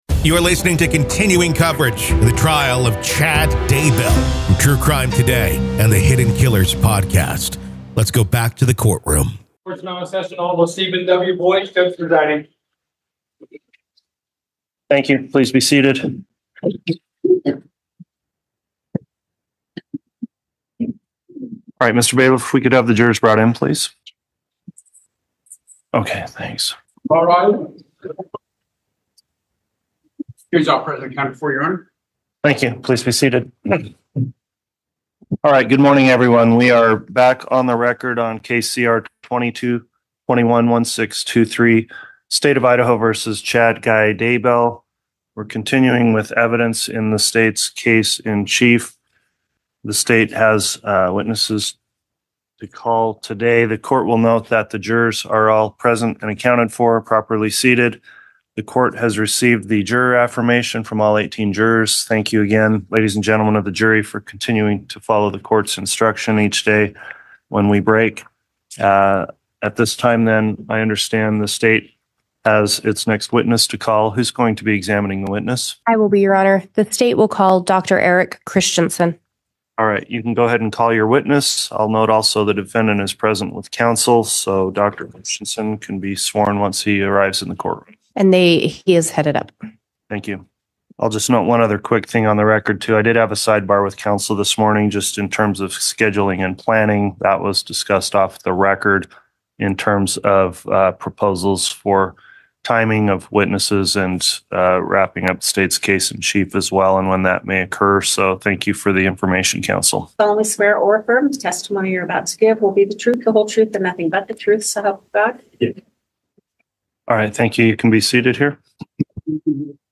The Trial Of Lori Vallow Daybell | Full Courtroom Coverage / The Trial of Chad Daybell Day 14 Part 1